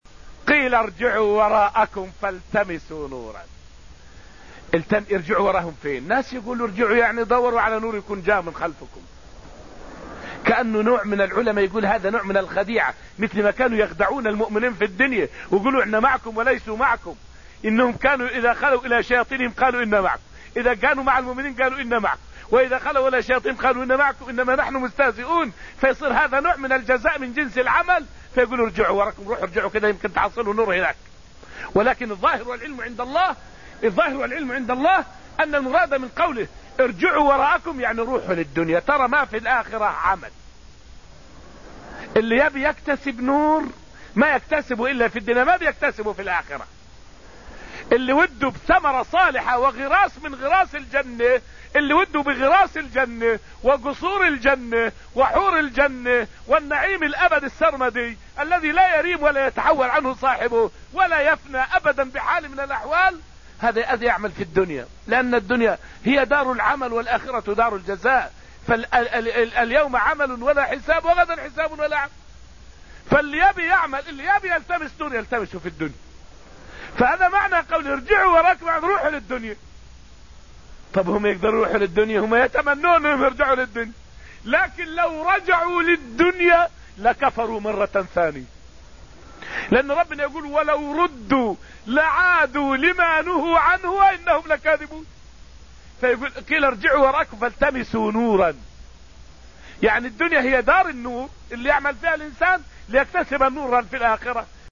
فائدة من الدرس الخامس عشر من دروس تفسير سورة الحديد والتي ألقيت في المسجد النبوي الشريف حول معنى قوله تعالى: {ارجعوا ورائكم فالتمسوا نورا}.